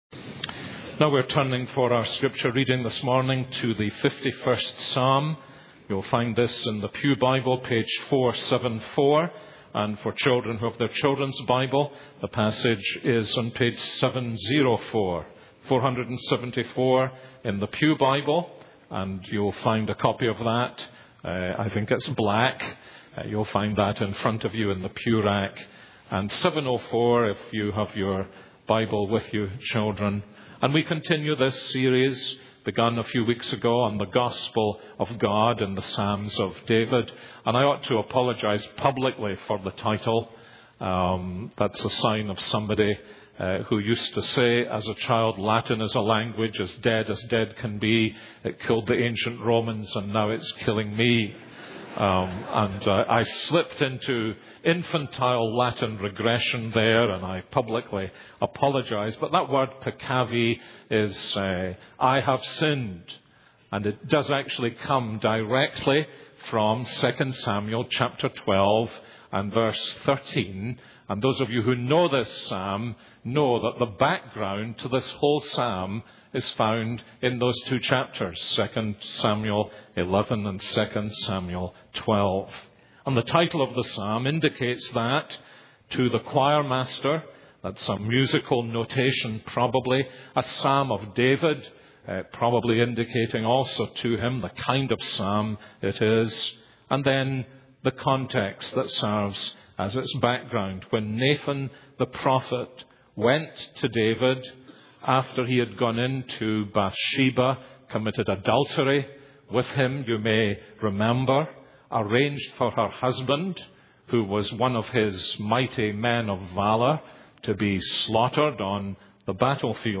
This is a sermon on Psalm 51:1-19.